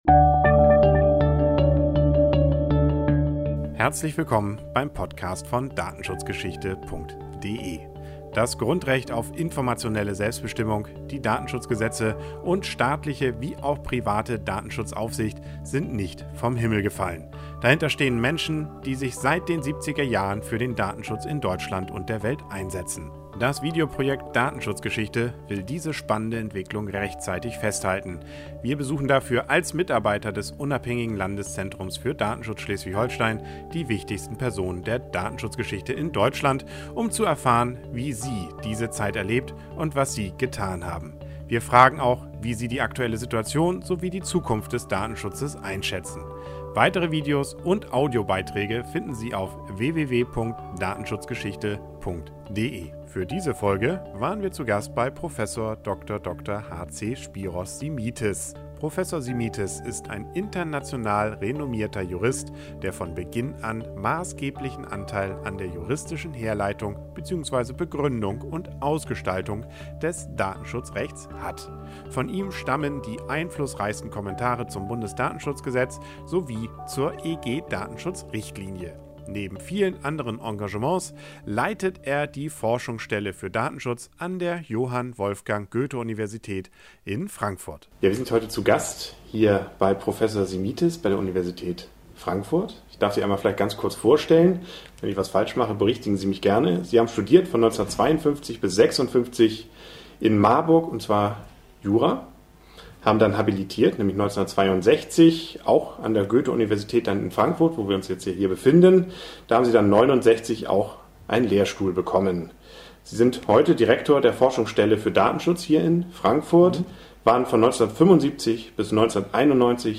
Spiros Simitis im Interview.
interview-simitis.mp3